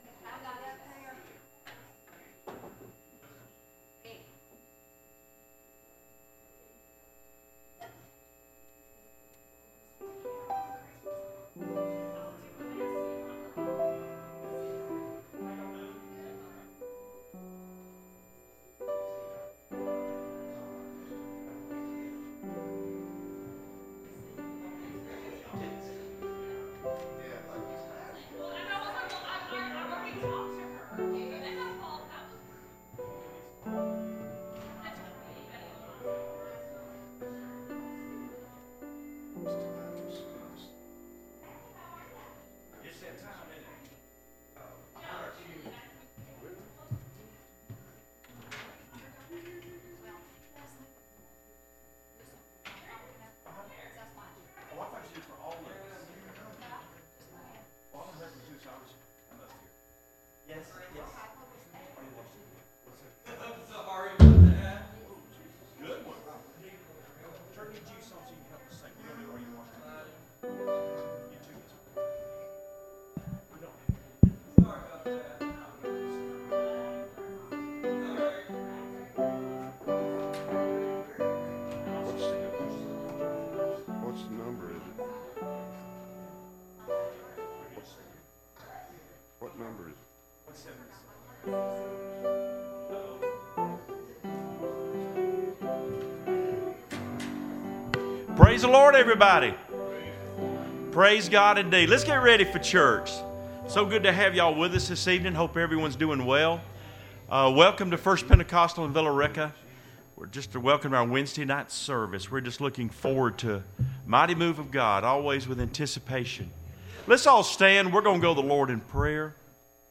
Testimony Service